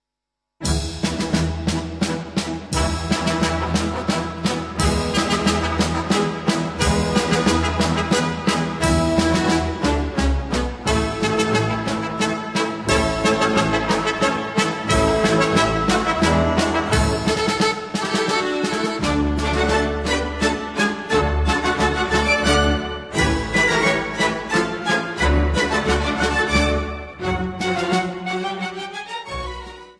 (Nagrania archiwalne z lat 1962-1974)